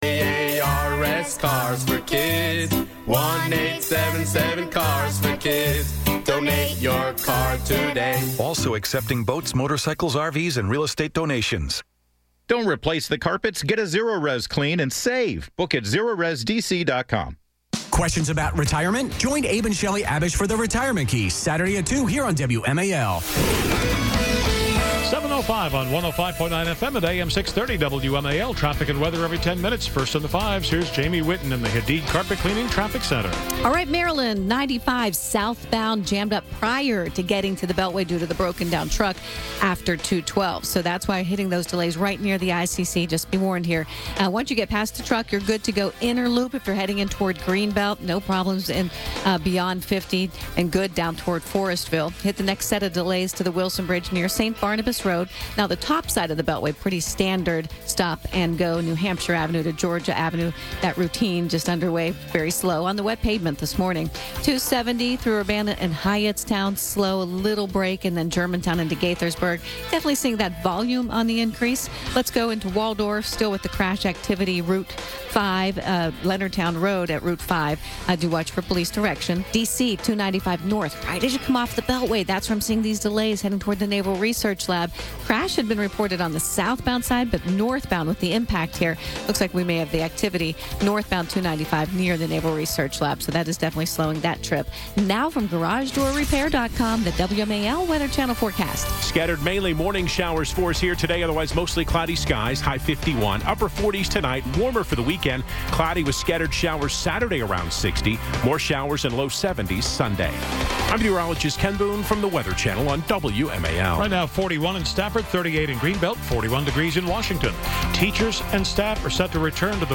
broadcast live from CPAC